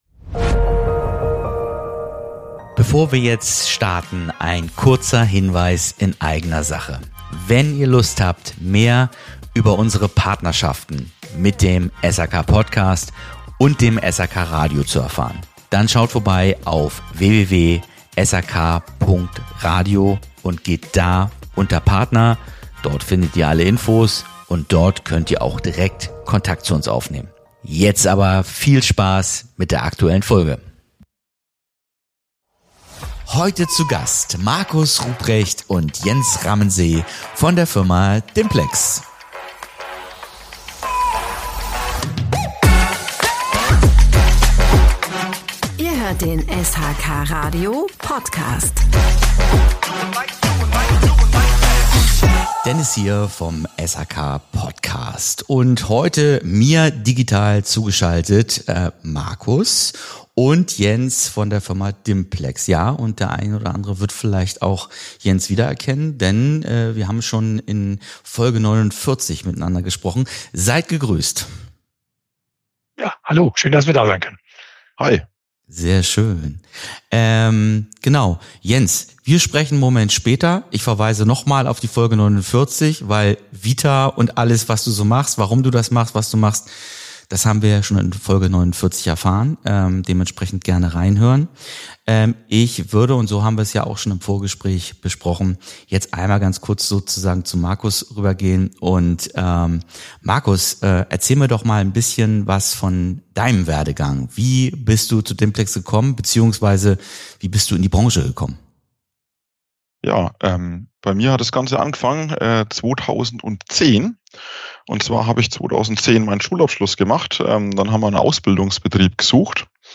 In dieser SHK PODCAST-Folge sprechen wir mit Experten von Dimplex über das neue System C – eine leistungsstarke Luft/Wasser-Wärmepumpe, entwickelt für große Wohnkomplexe und Gewerbeobjekte.